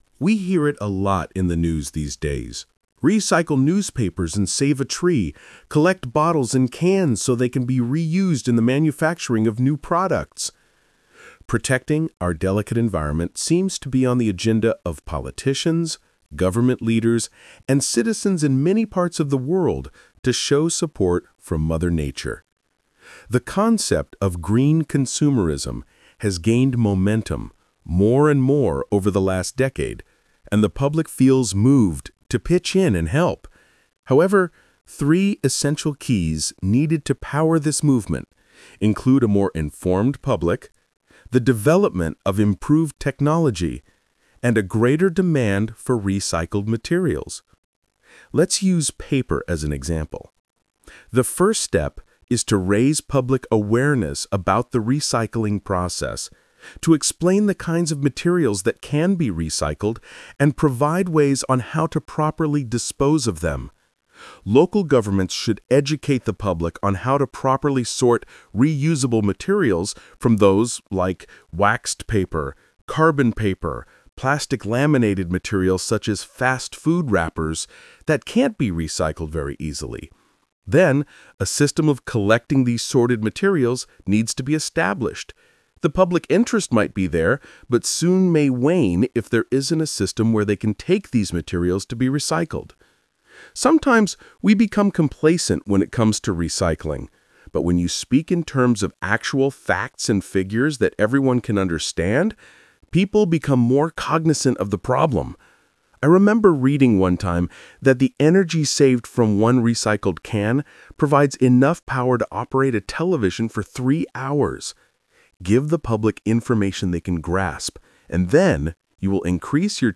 Talk/Lecture 3: You will hear a talk about recycling.